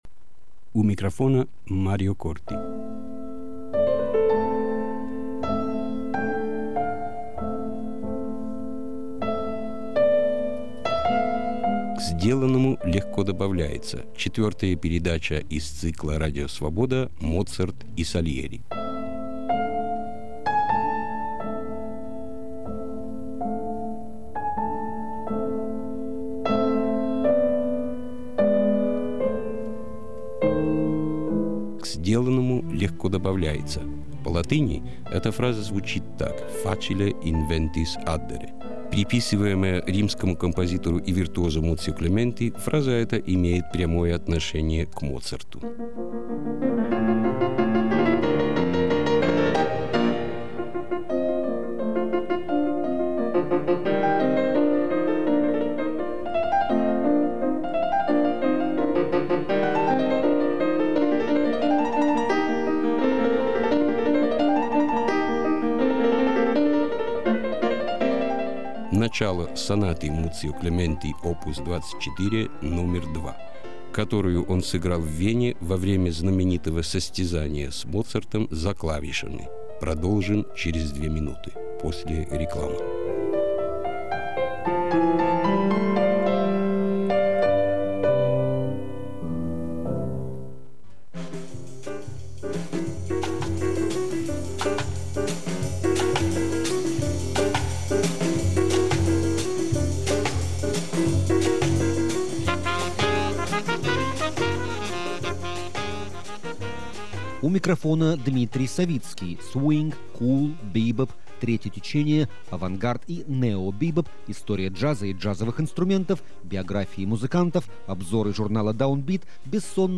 Приписываемая римскому композитору и виртуозо Муцио Клементи, фраза эта имеет прямое отношение к Моцарту. [Clementi, начало сонаты Муцио Клементи опус 24, номер 2] которую он сыграл в Вене, во время...